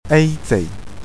例：”欧洲”【eu44-tseu31】的读法    错误的版本